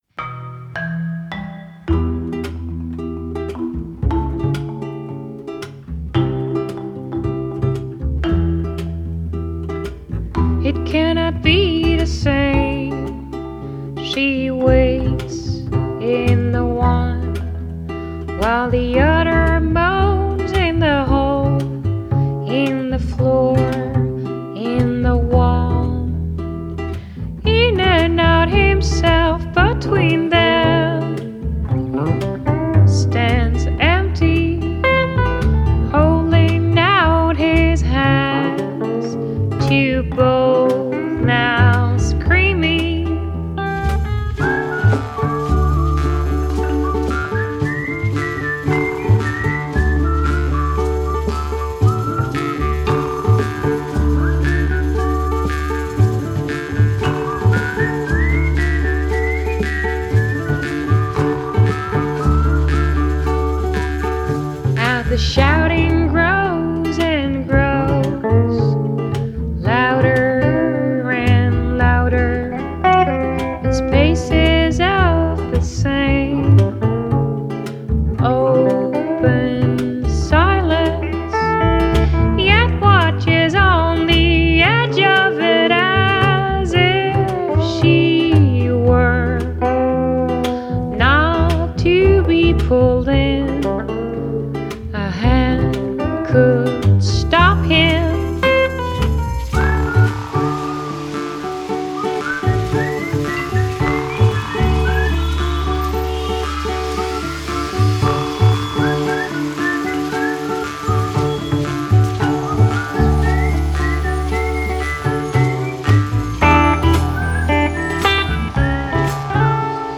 Genre: Folk, Indie, Country Folk, Female Vocal